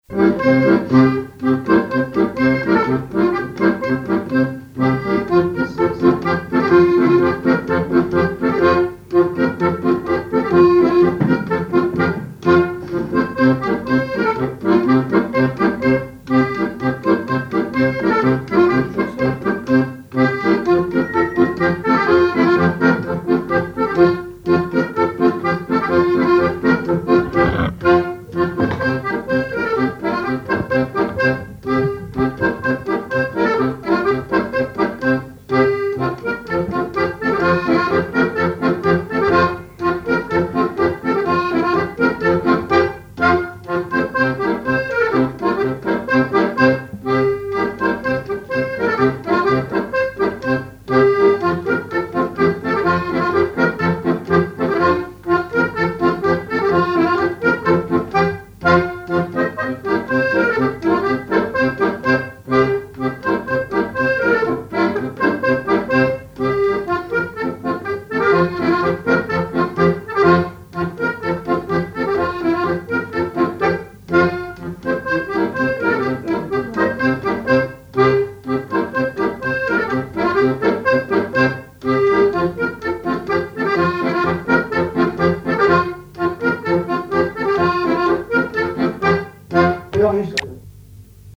danse : branle : courante, maraîchine
accordéon chromatique
Pièce musicale inédite